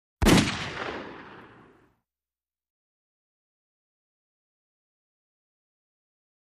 Automatic Weapon 1, Single Shot, In Echoey Canyon.